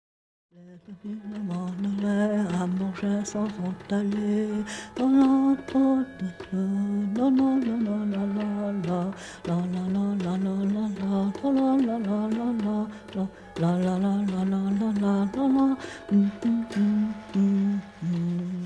Carillon de Champéry: Les jeunes filles du Val d’Illiez (1) – The young girls from the valley of Illiez (1)